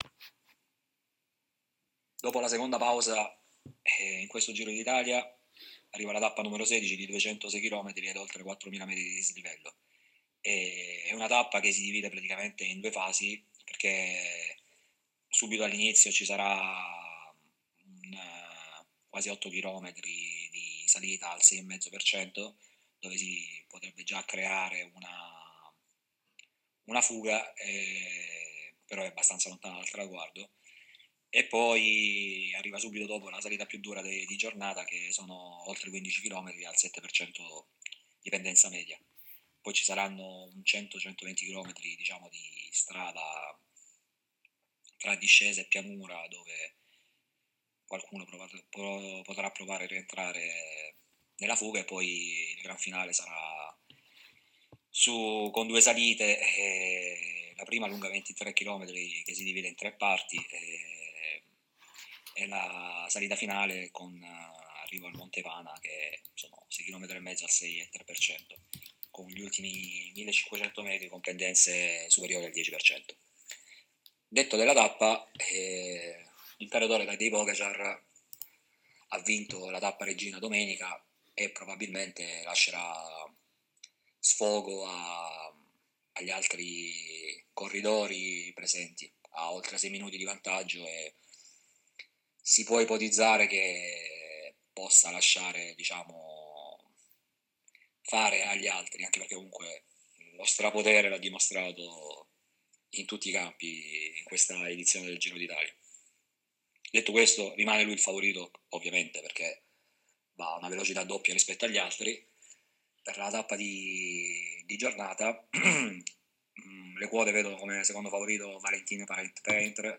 Le idee del nostro tipster appassionato di Ciclismo per la tappa odierna del 21 maggio 2024: